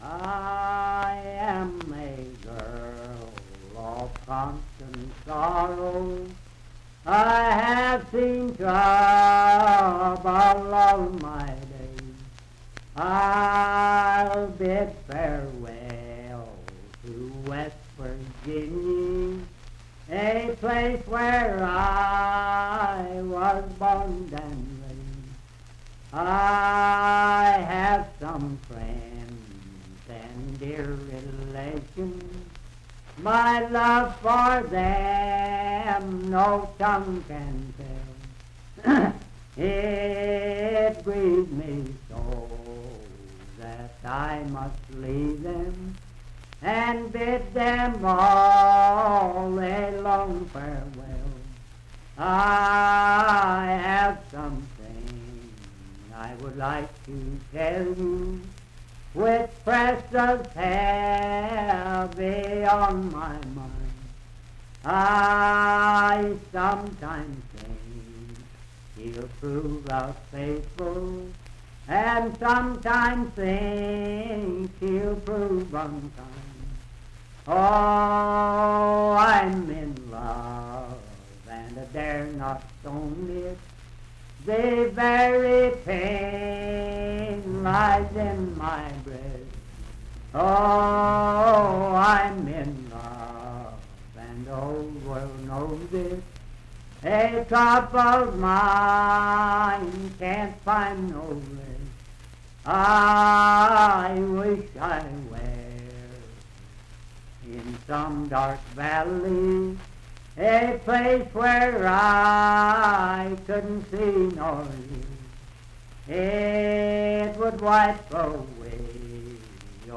Unaccompanied vocal music and folktales
Voice (sung)
Wood County (W. Va.), Parkersburg (W. Va.)